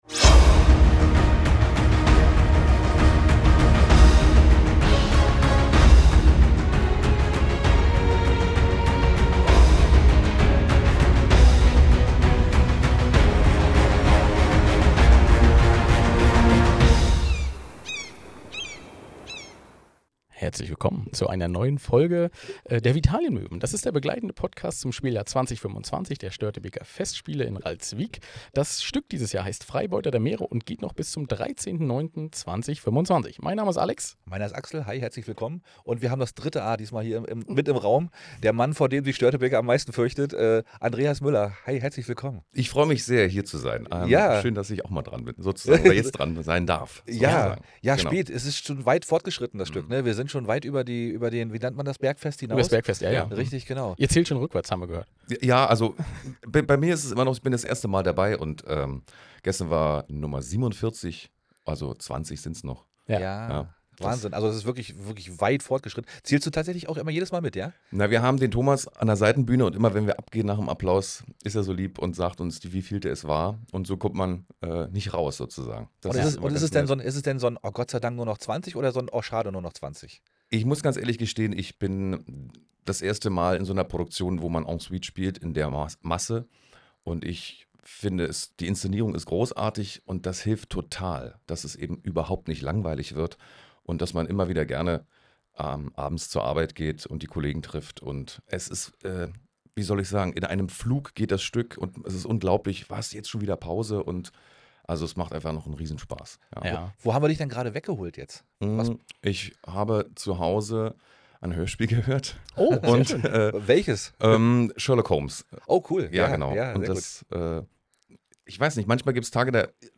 Was für eine Stimme! Der sympathische Bösewicht des diesjährigen Stückes wird uns und euch heute Sirenengleich in seinen Bann ziehen. Freut euch auf ein spannendes Interview und ein knappe Stunde feinste Unterhaltung.